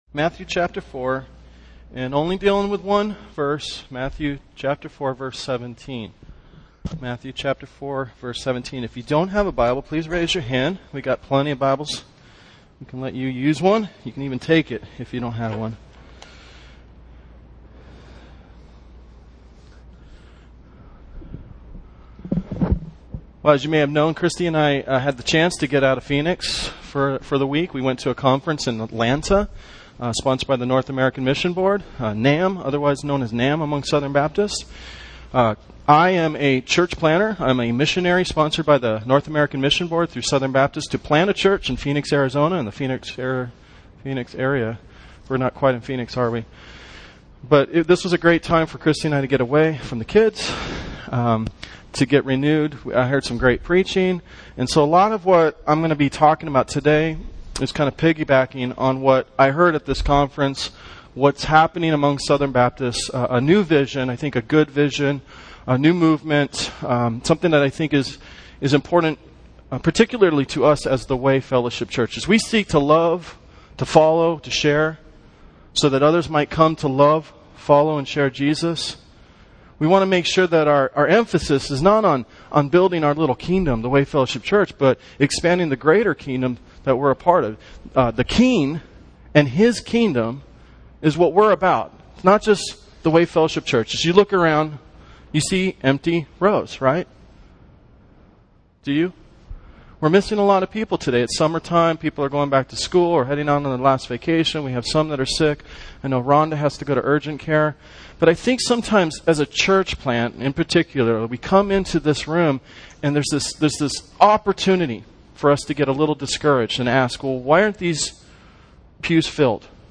A Stand Alone Sermon